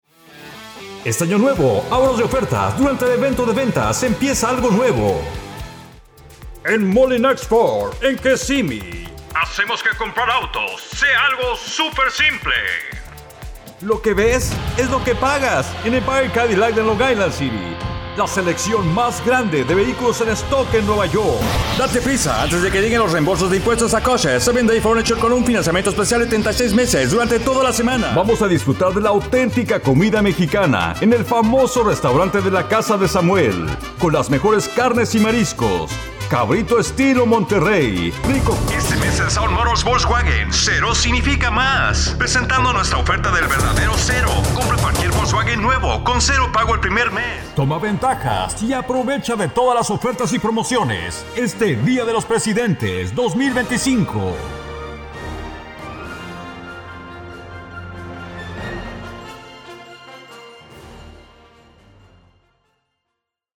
0116Male_Commercials.mp3